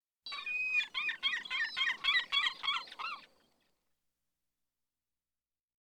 Звуки чаек
Крики этих птиц создают атмосферу побережья, подходят для медитации, звукового оформления и релаксации.
Нга-га-га — крик одинокой чайки